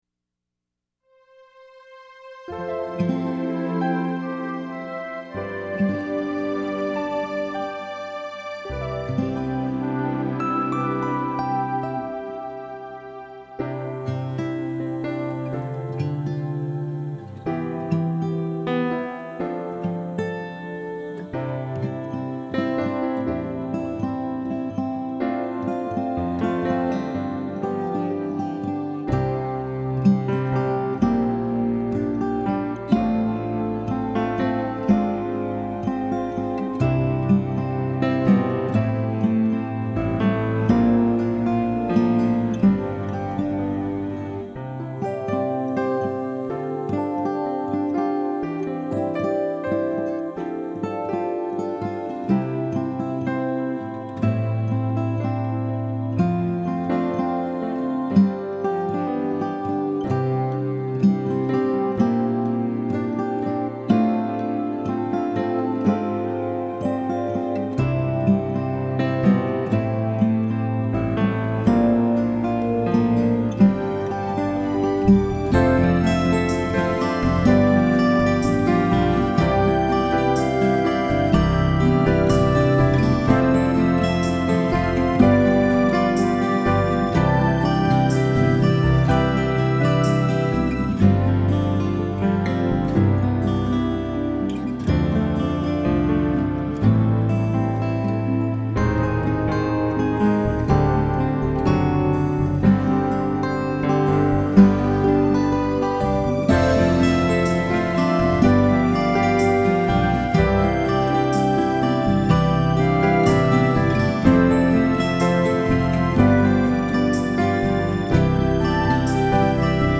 my-dream-2-guitar-mix.mp3